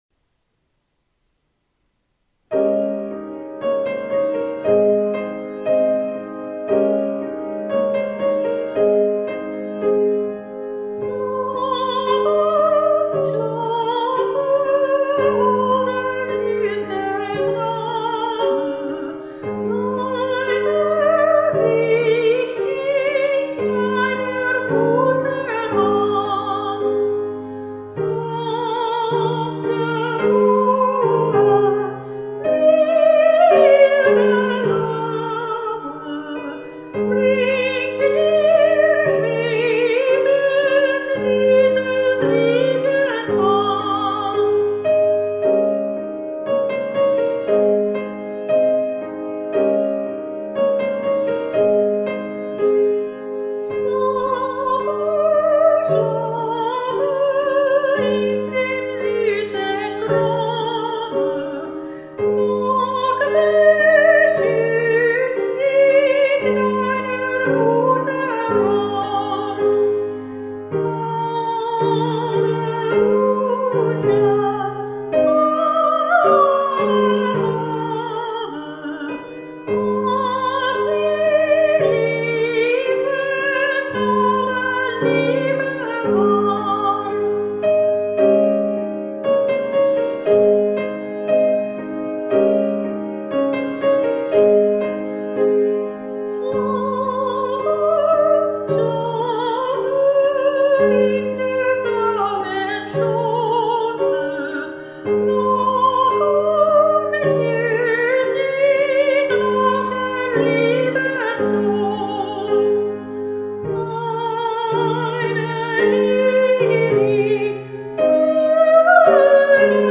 《摇篮曲》是舒伯特的著名代表作品之一。歌曲节奏平稳而有规律，充满了温馨、静谧的气氛。